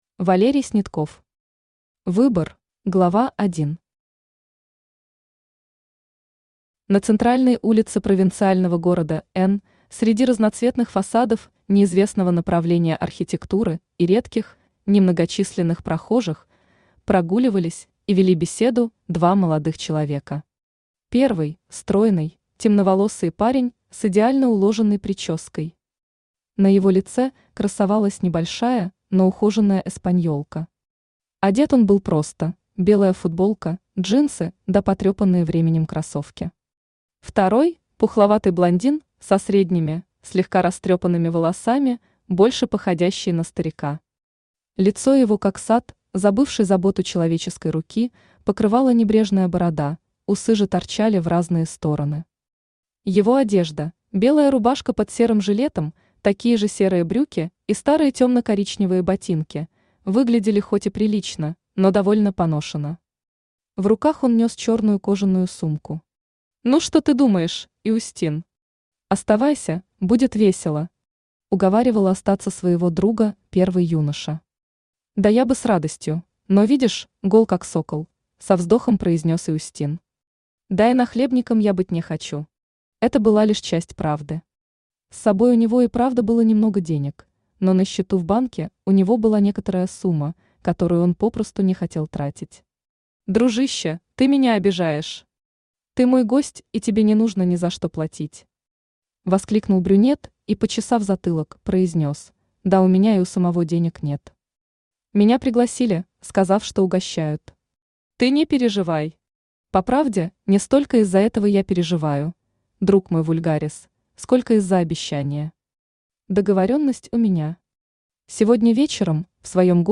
Аудиокнига Выбор | Библиотека аудиокниг
Aудиокнига Выбор Автор Валерий Снятков Читает аудиокнигу Авточтец ЛитРес.